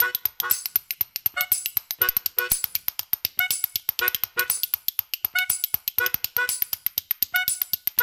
Spoons